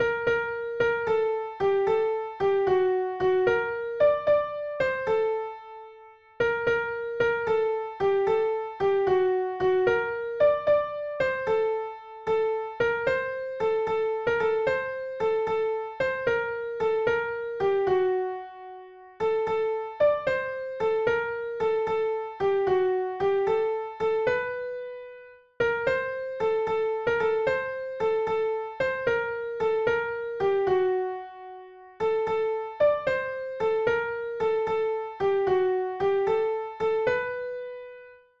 Tenor Part
Hearts-Ease-Tenore-Part-2.mp3